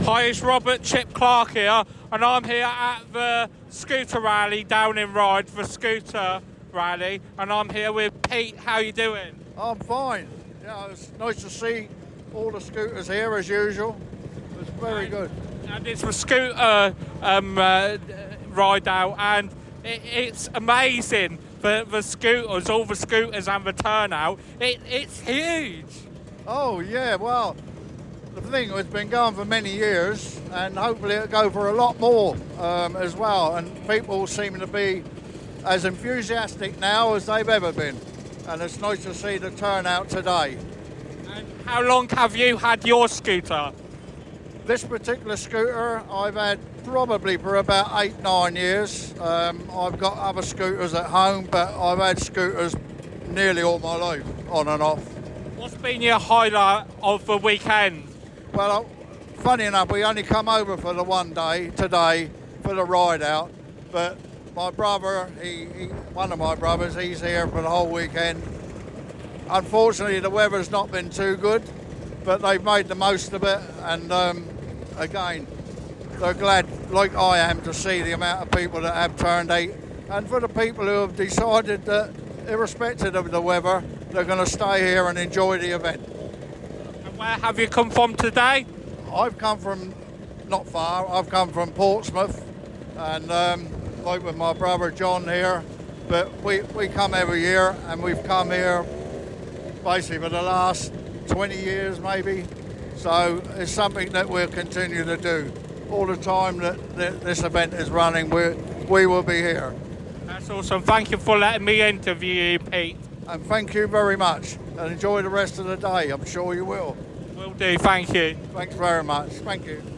Scooter Ride Out Interview 2024